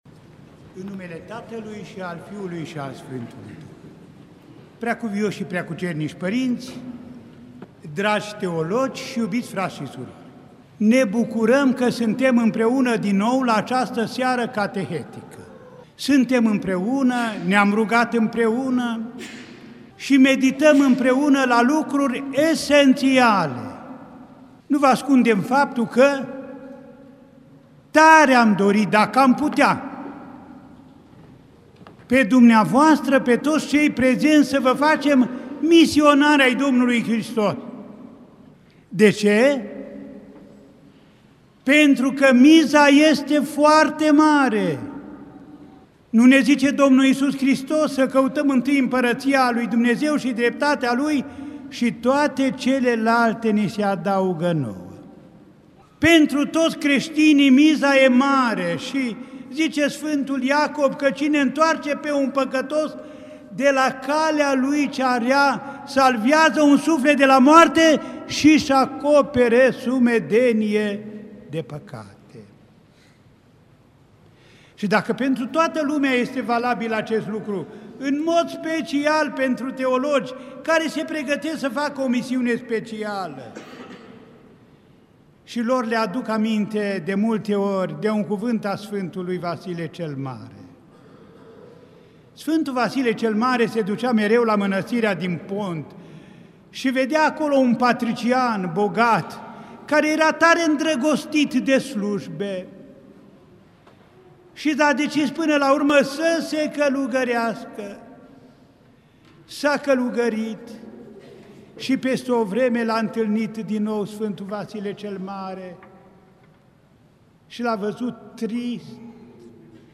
Cateheza – Viu este Dumnezeu – Plinirea vremii